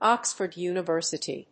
アクセントÓxford Univèrsity